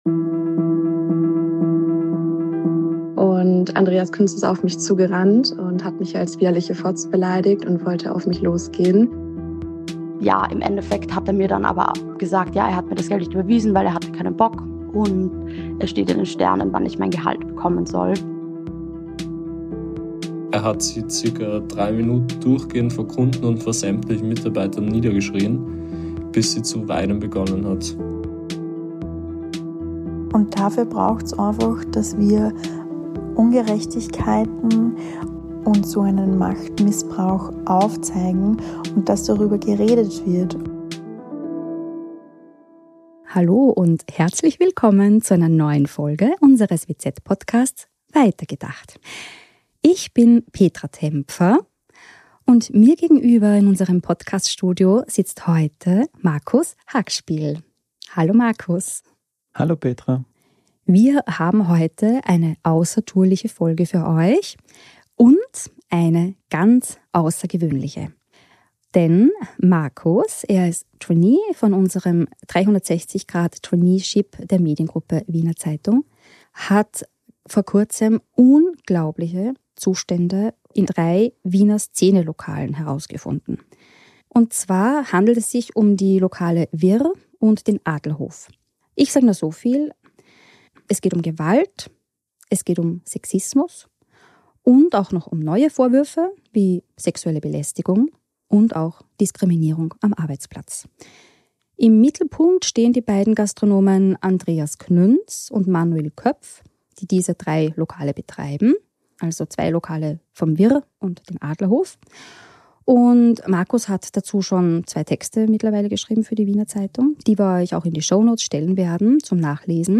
eine von vielen Ex-Mitarbeiter:innen der Wiener Szene-Lokale Wirr und Adlerhof, die uns ihre Erfahrungen in Sprachnachrichten erzählt haben. Andere berichten von Diskriminierung, Wutausbrüchen, absichtlich zu spät ausbezahlten Löhnen und weinenden Gästen.